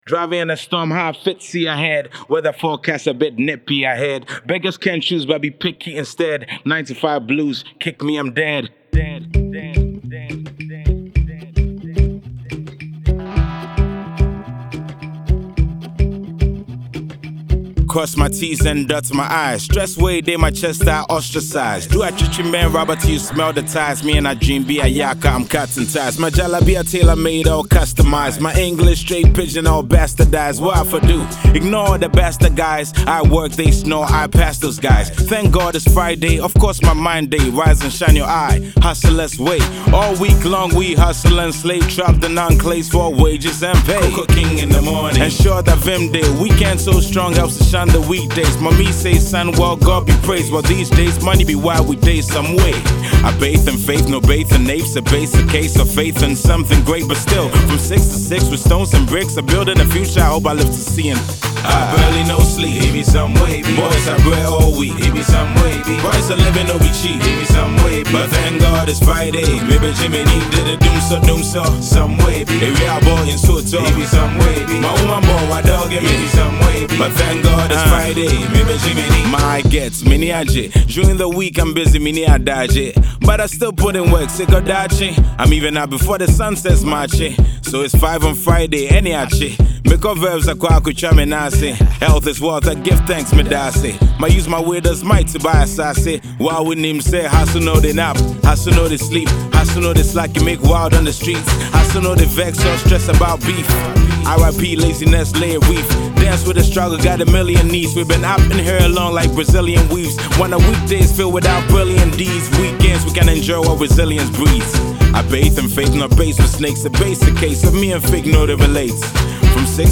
over a thumping Hip-Hop beat with an afrobeat swing